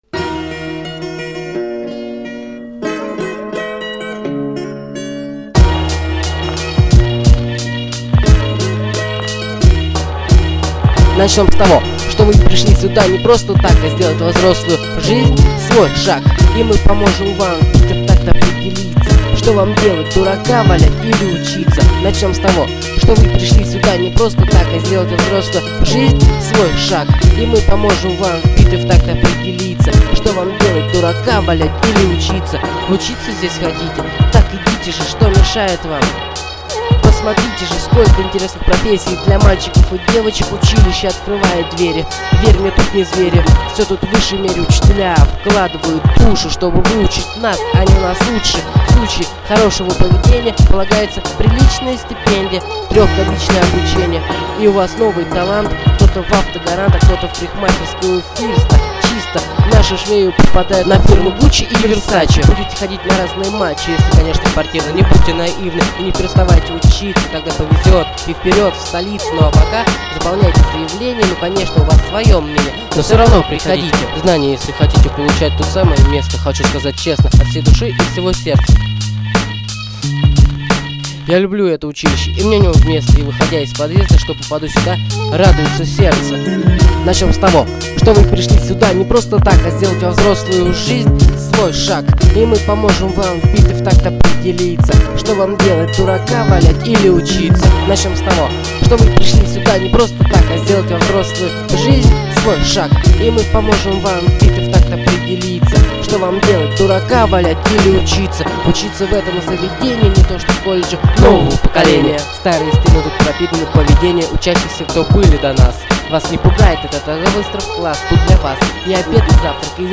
за качество извеняюсь! иногда слигком близко был к микрофону)